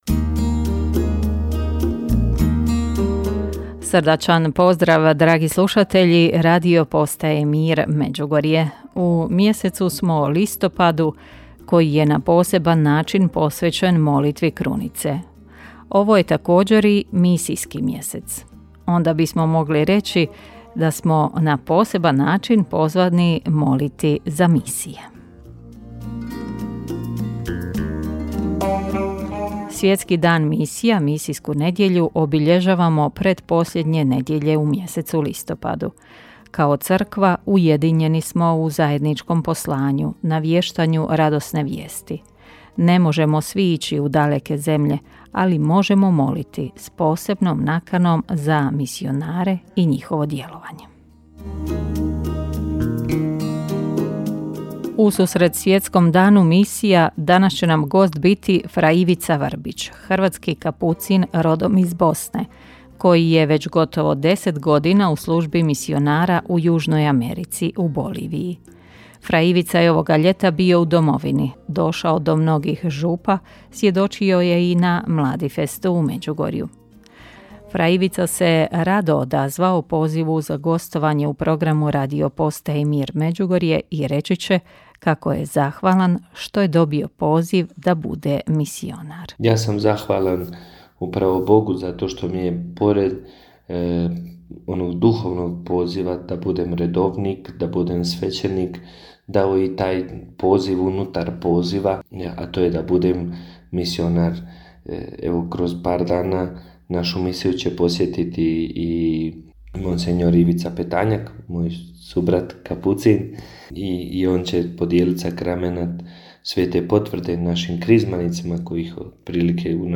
Ususret tome danu ugostili smo u programu Radiopostaje Mir Međugorje hrvatskog misionara na službi u Boliviji